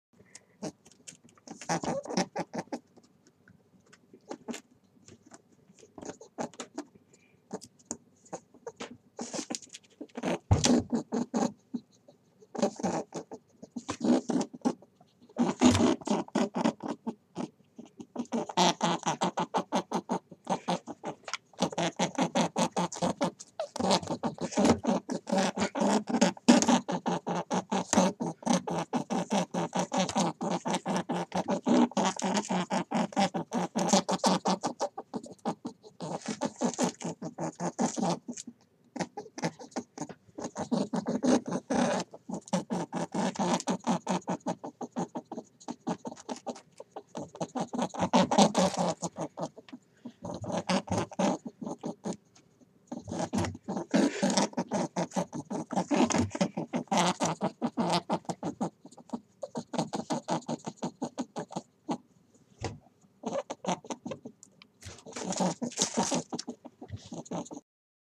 Звуки кролика
Кролик громко кричит